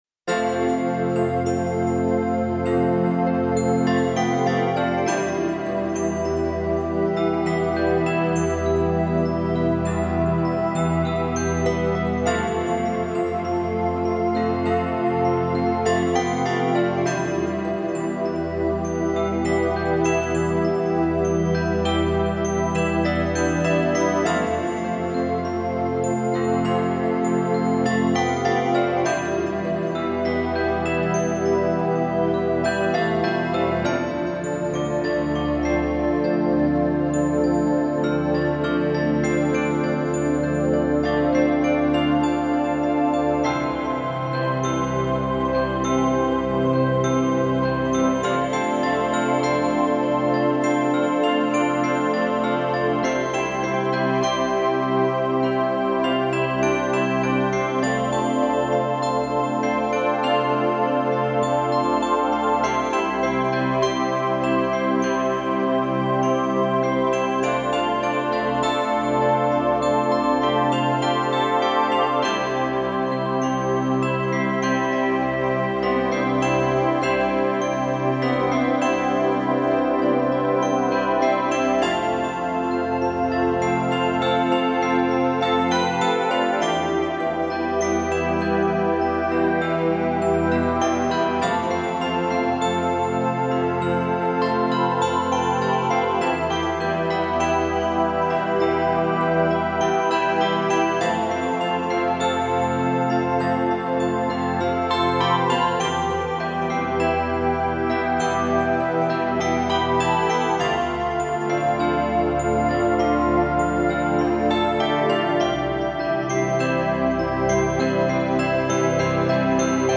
这是一套NEW AGE风格的唱片
以波澜起伏的优美旋律作为主调，尽量使听众从重压中解脱出来~